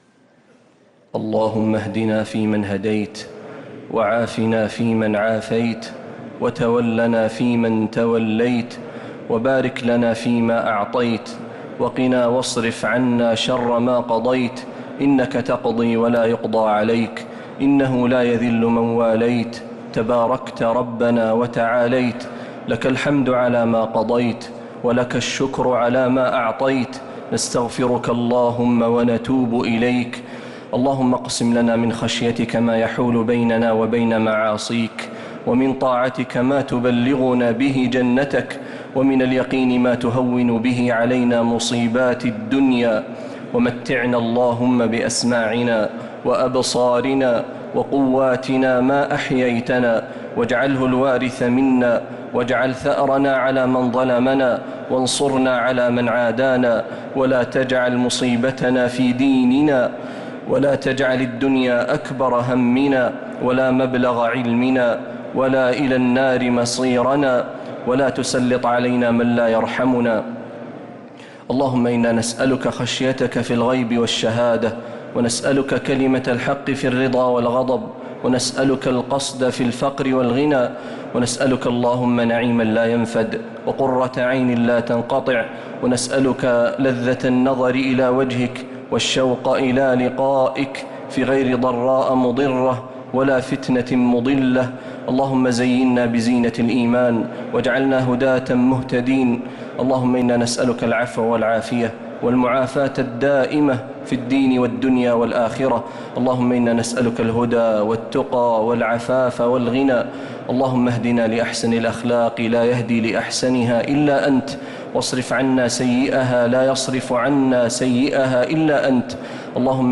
دعاء القنوت ليلة 19 رمضان 1446هـ | Dua 19th night Ramadan 1446H > تراويح الحرم النبوي عام 1446 🕌 > التراويح - تلاوات الحرمين